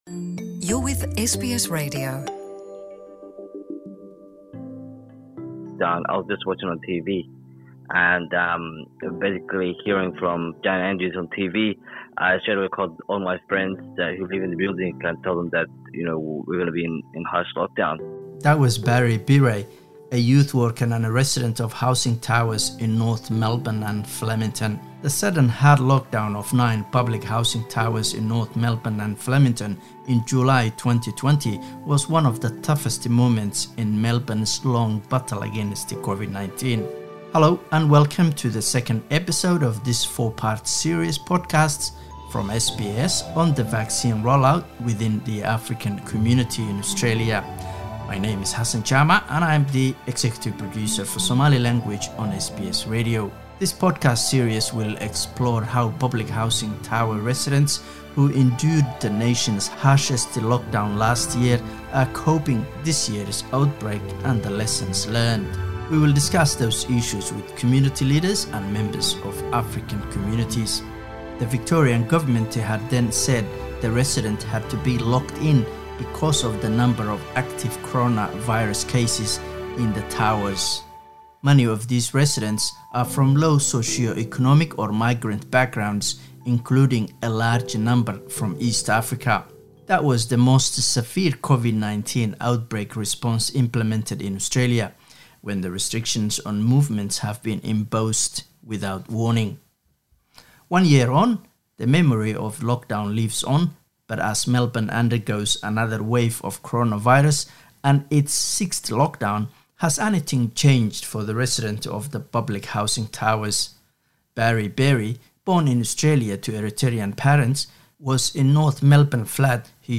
In the second part of our podcast series, ‘Pathway Out of the Pandemic,’ we bring you voices of some African-Australian residents of the Flemington and North Melbourne public housing towers, who say lessons have been learned from the hard lockdown of 2020.